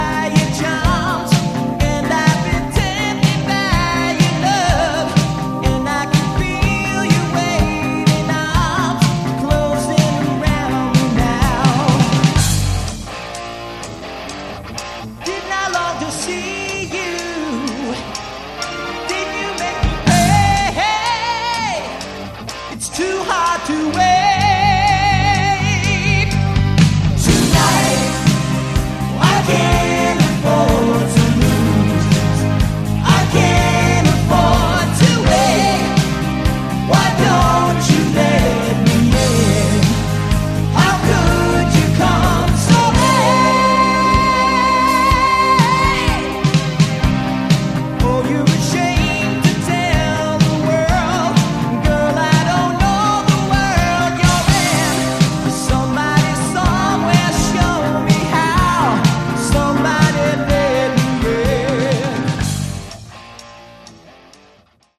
Category: Pomp AOR
lead vocals, keyboards
guitars, vocals
bass, vocals
drums